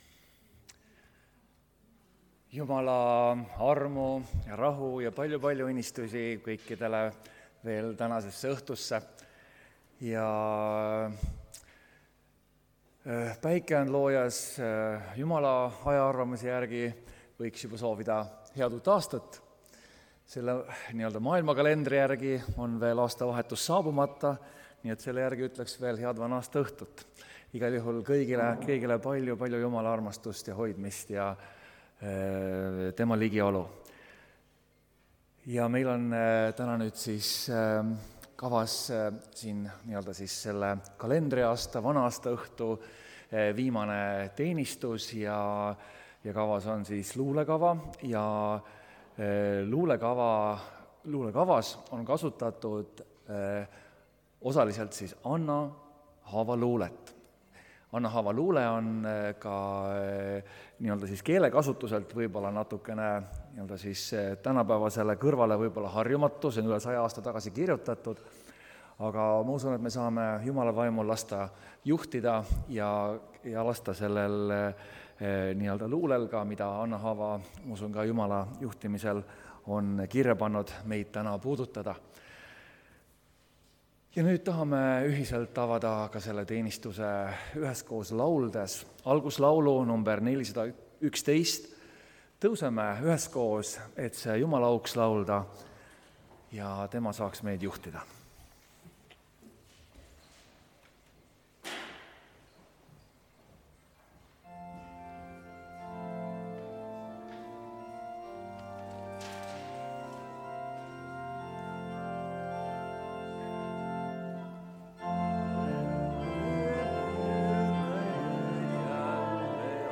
Aastavahetuse luulekava (Tallinnas)